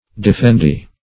Search Result for " defendee" : The Collaborative International Dictionary of English v.0.48: Defendee \De`fen*dee"\ (d[-e]`f[e^]n*d[=e]" or d[-e]*f[e^]nd"[=e]`), n. One who is defended.